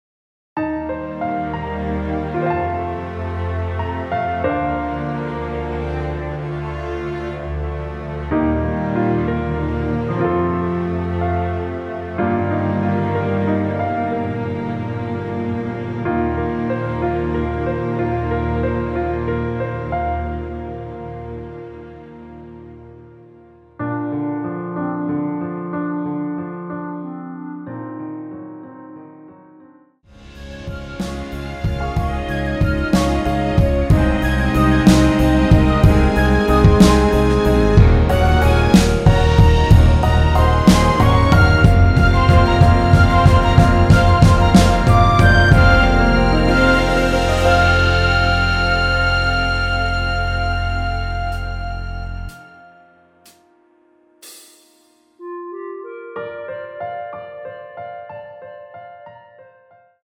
3분 14초경 무반주 후 노래 들어가는 부분 박자 맞추기 쉽게 카운트 추가하여 놓았습니다.(미리듣기 확인)
원키에서(-3)내린 멜로디 포함된 MR입니다.
Bb
앞부분30초, 뒷부분30초씩 편집해서 올려 드리고 있습니다.